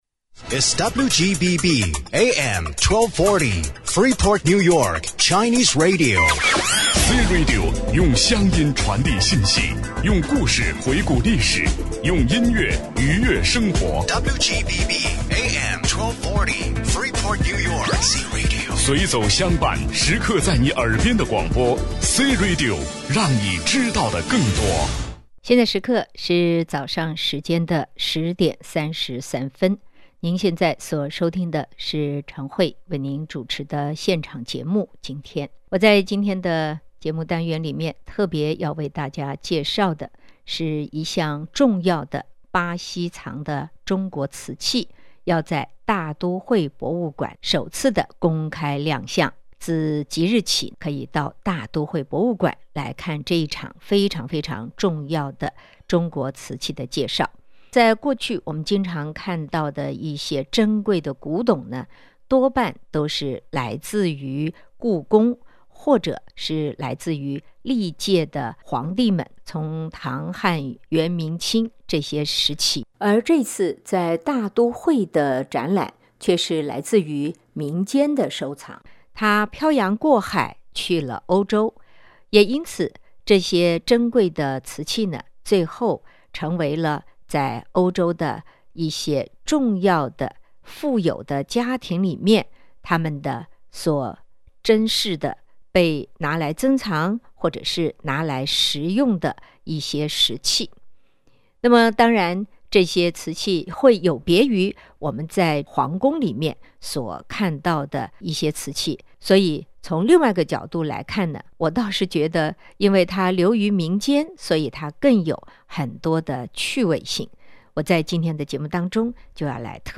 MetropolitanMuseumofArt-interviewMay2016.mp3